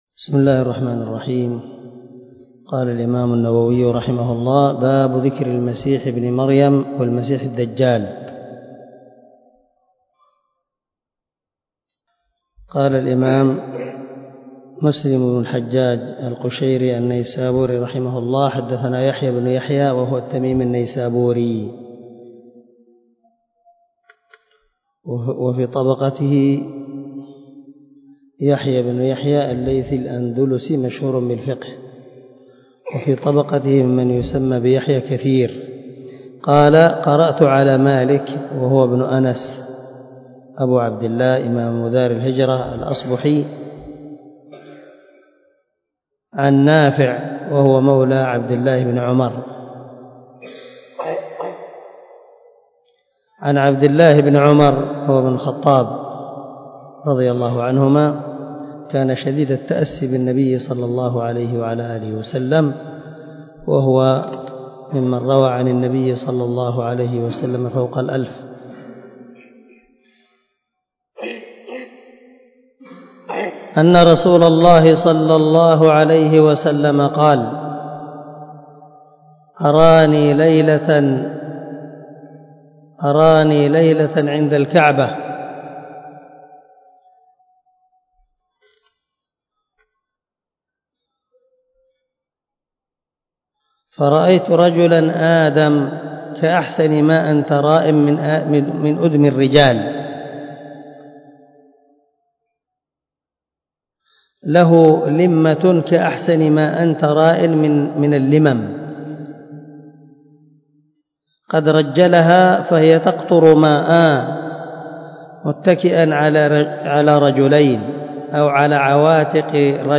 127الدرس 126 من شرح كتاب الإيمان حديث رقم ( 169 ) من صحيح مسلم
دار الحديث- المَحاوِلة- الصبيحة.